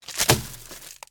trashcan2.ogg